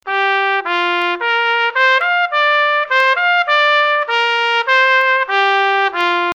trumpet,
tptriff.mp3